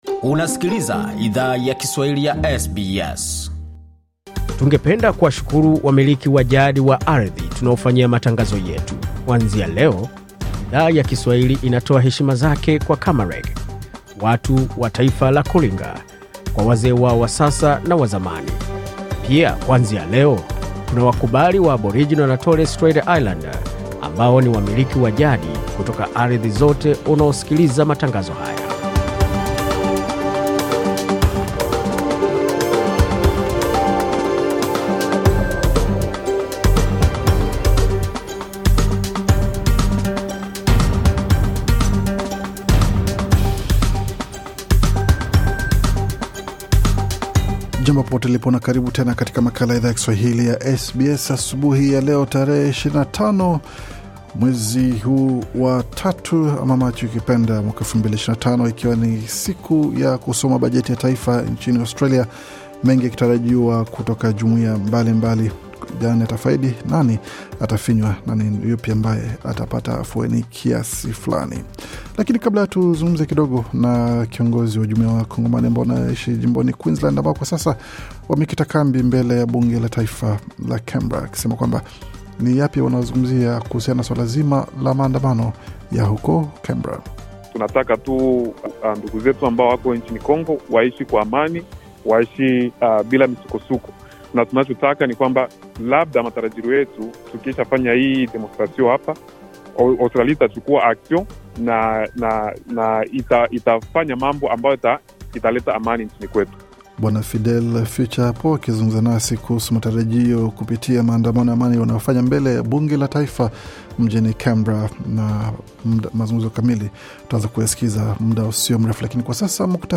Taarifa ya Habari 25 Machi 2025